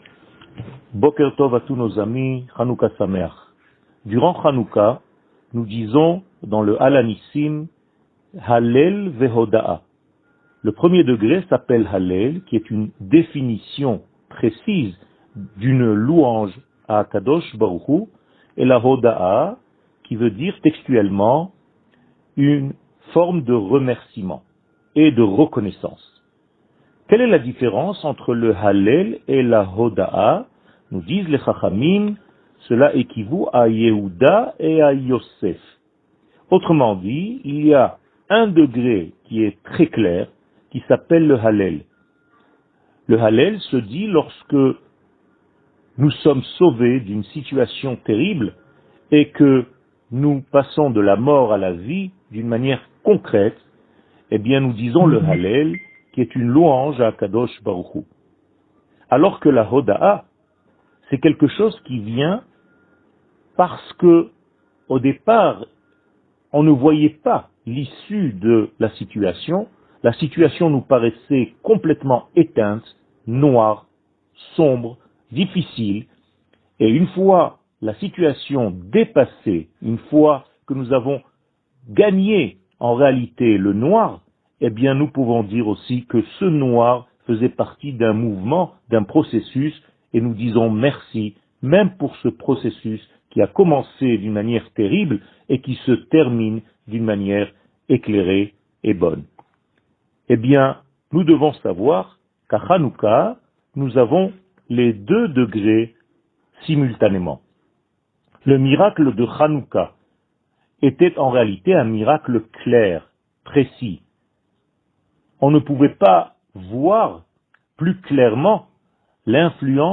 שיעור מ 14 דצמבר 2020